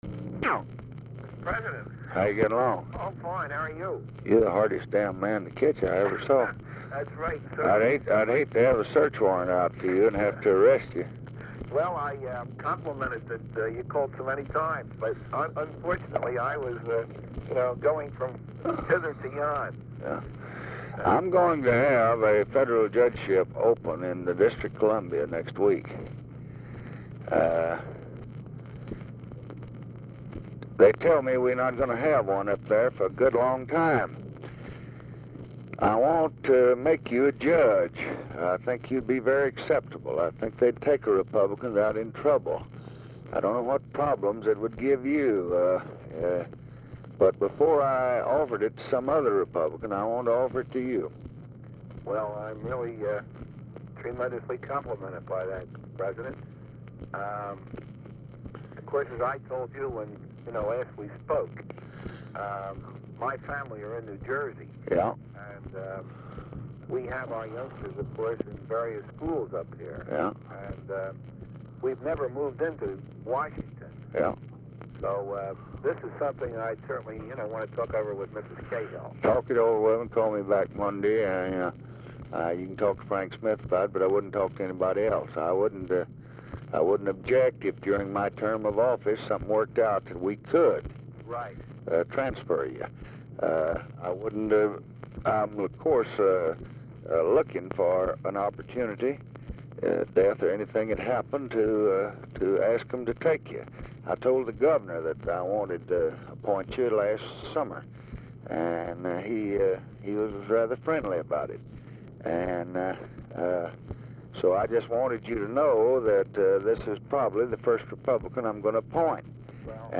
Telephone conversation # 6864, sound recording, LBJ and WILLIAM CAHILL, 2/20/1965, 11:43AM | Discover LBJ
Format Dictation belt
Location Of Speaker 1 Mansion, White House, Washington, DC
Specific Item Type Telephone conversation Subject Appointments And Nominations Congressional Relations Judiciary National Politics